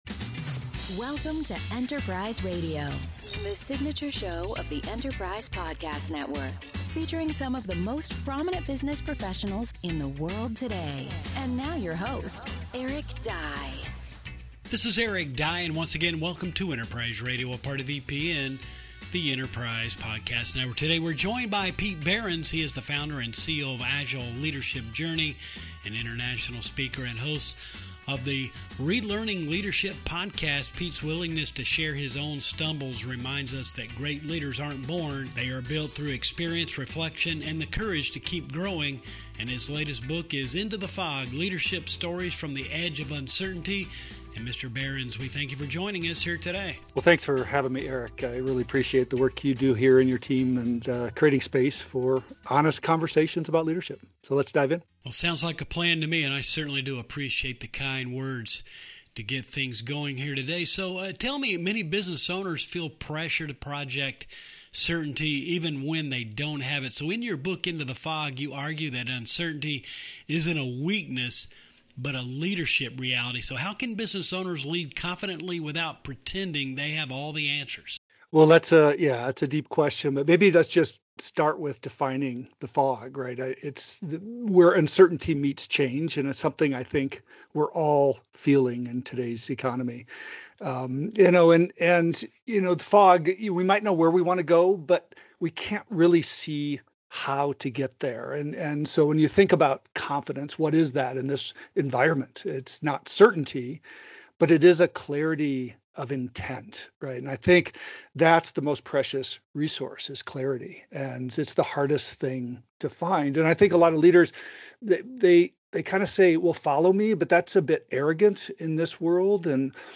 In this Enterprise Radio interview